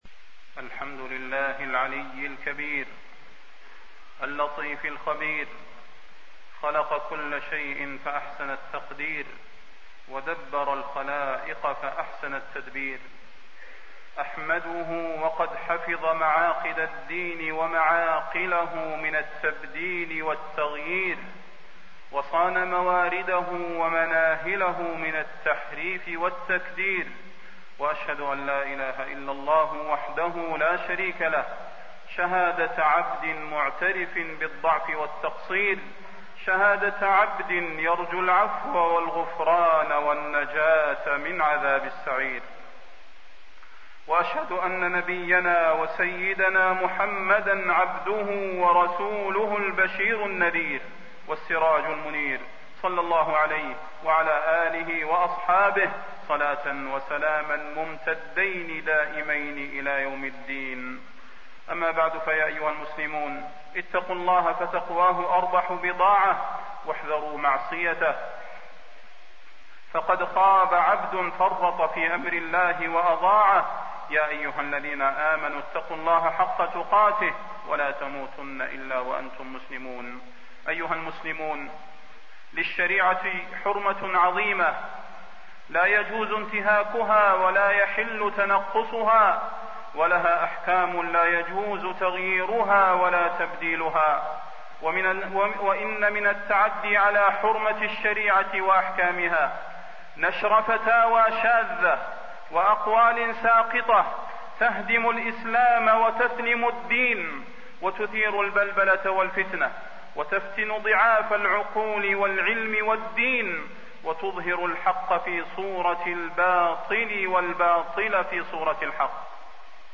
تاريخ النشر ١٦ جمادى الأولى ١٤٣١ هـ المكان: المسجد النبوي الشيخ: فضيلة الشيخ د. صلاح بن محمد البدير فضيلة الشيخ د. صلاح بن محمد البدير الفتوى